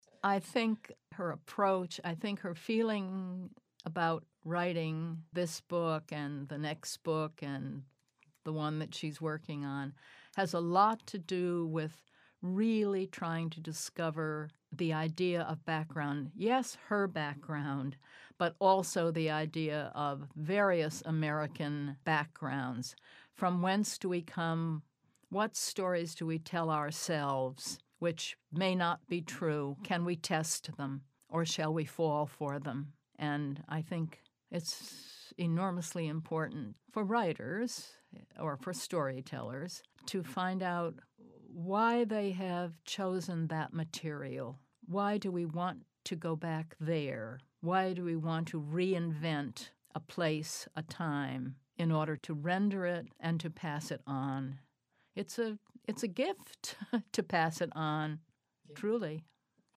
Writer Maureen Howard talks about why Julie Otsuka writes.